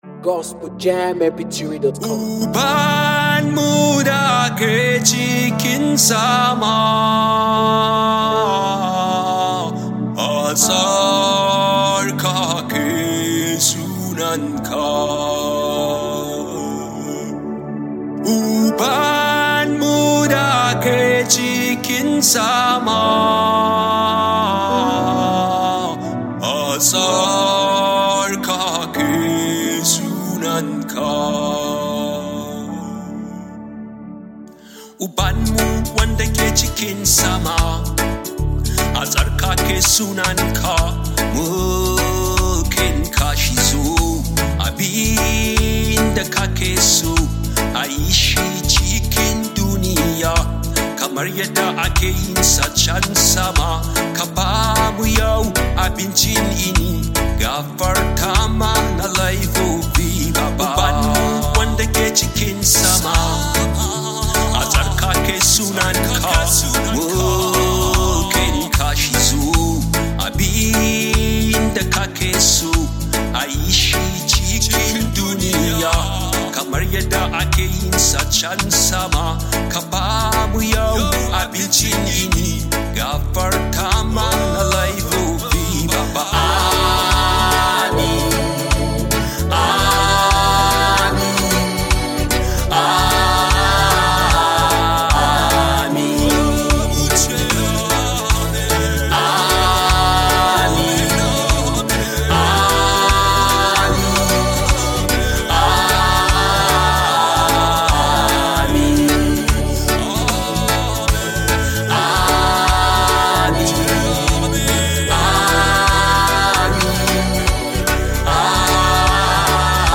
Nigeria base Gospel singer